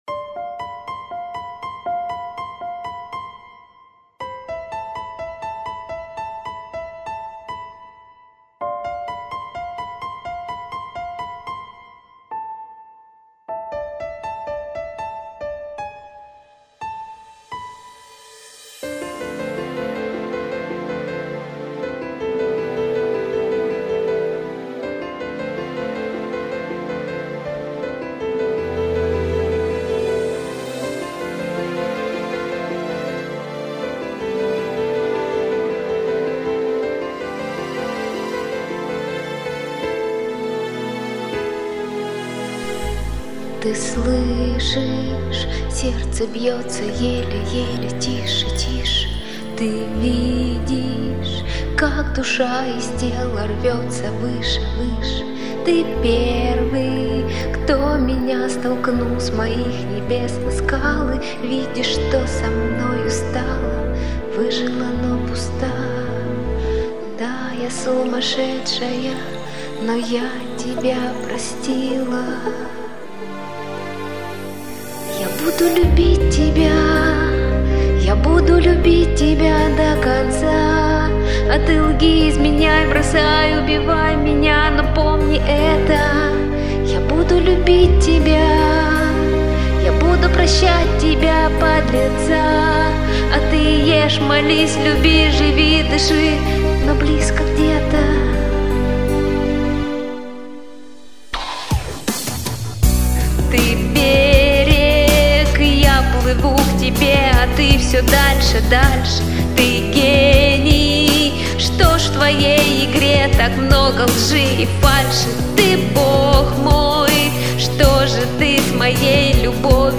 нежное исполнение